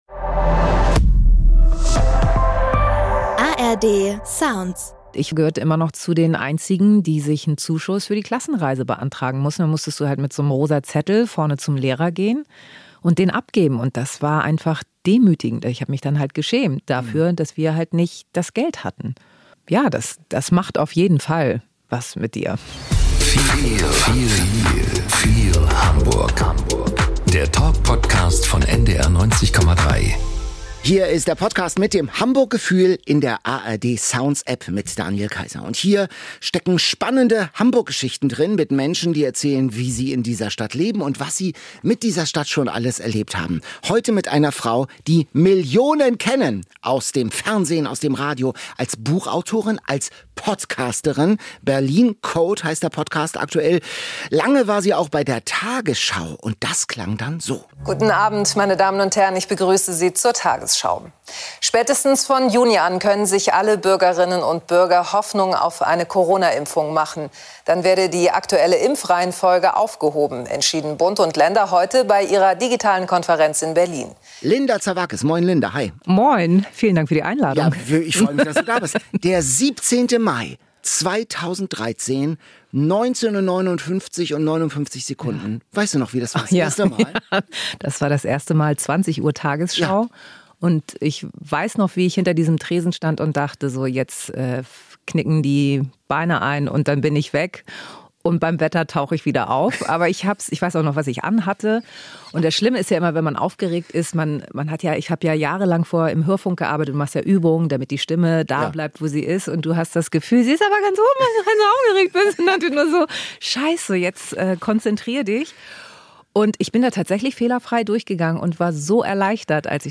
Linda Zervakis: Vom Kiosk zur Tagesschau ~ "Feel Hamburg" Podcast
Im Gespräch spricht sie offen über ihren Werdegang, ihre Kindheit in Hamburg-Harburg und die prägenden Erfahrungen im Kiosk ihrer Eltern.